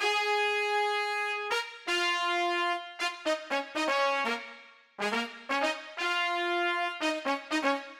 17 Brass PT2.wav